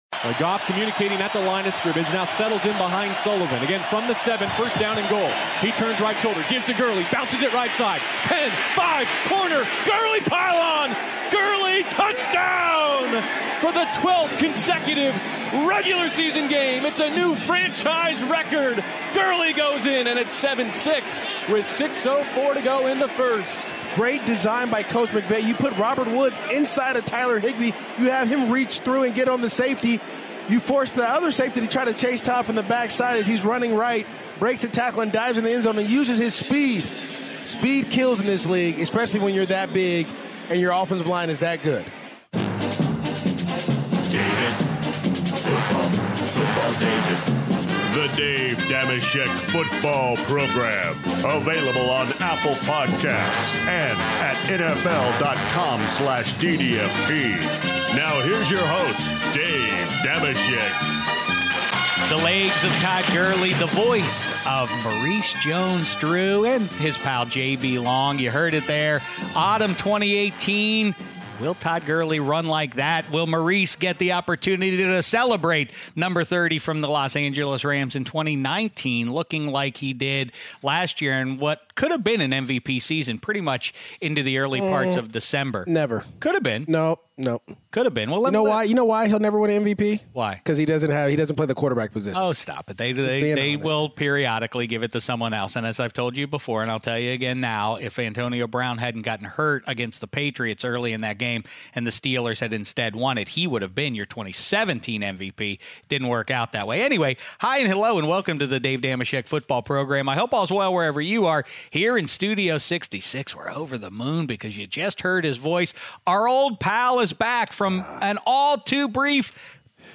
Dave Dameshek is joined in Studio 66 by our old pal Maurice Jones-Drew who is back from vacation.